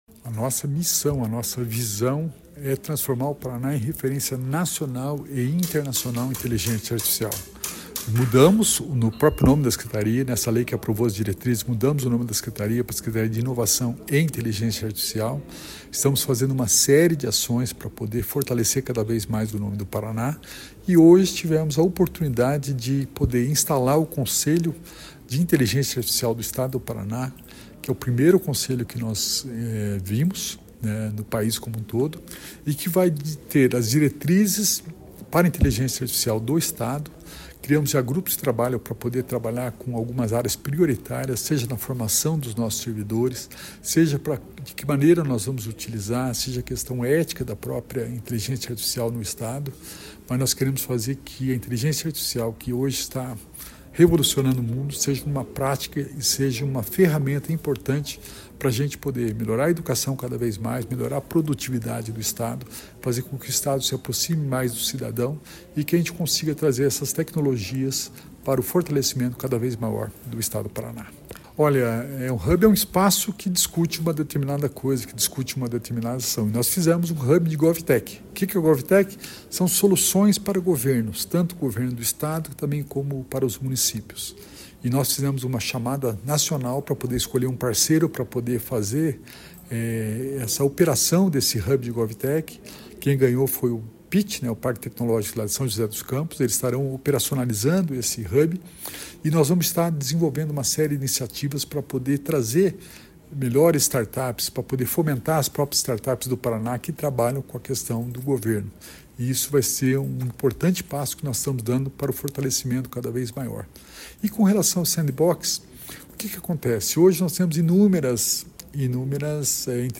Sonora do secretário da Inovação e Inteligência Artificial, Alex Canziani, sobre a primeira reunião do Conselho Estadual de Inteligência Artificial